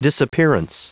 Prononciation du mot disappearance en anglais (fichier audio)
Prononciation du mot : disappearance